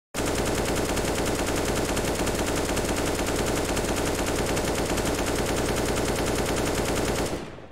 Cs go Ak-47 Firing Sound effect